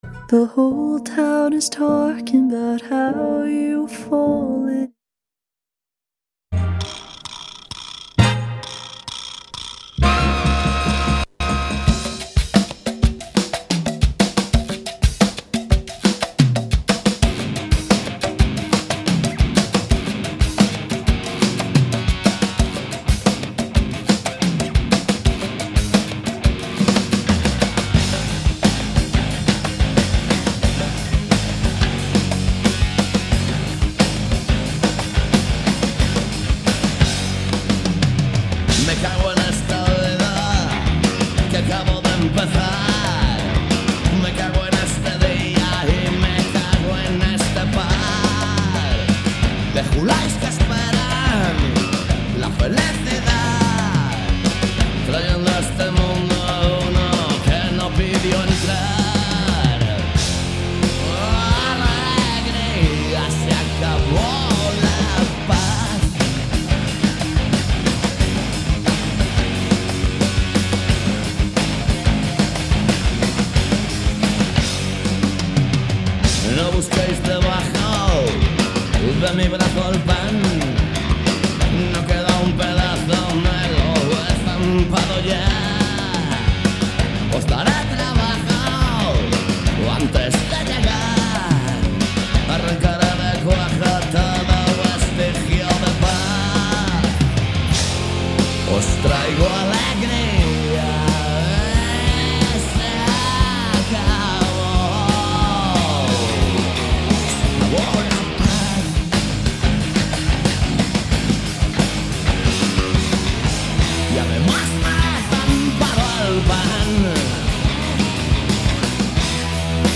Todo iso misturado con boa música e un pouco de humor se o tema o permite. Cada martes ás 18 horas en directo.
Alegría comezou a emitirse en outubro de 2003 e dende entón non falla nas ondas de CUAC FM.